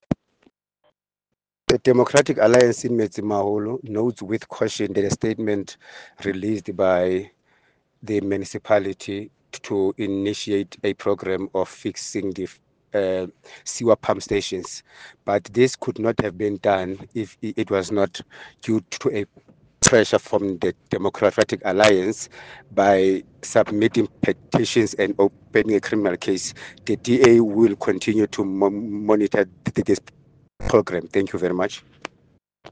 Sesotho soundbites by Cllr Thulani Mbana.